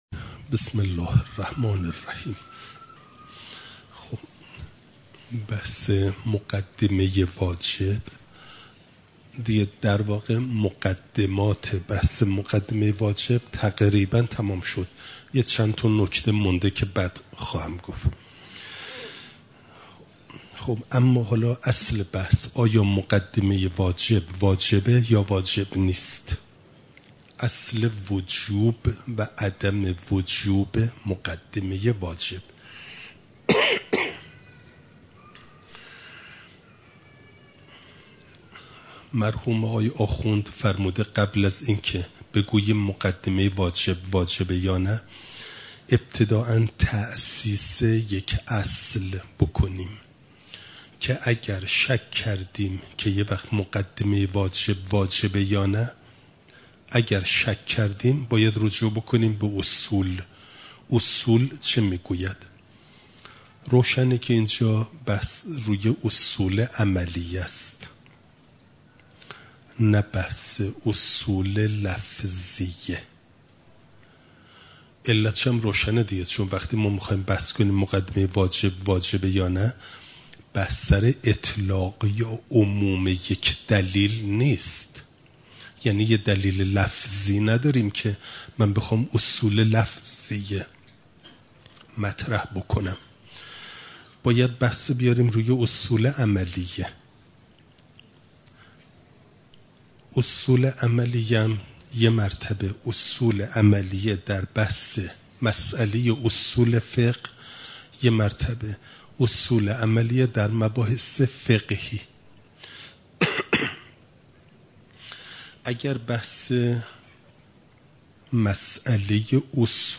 خارج اصول، مقدمه واجب (جلسه ۵۶) « دروس استاد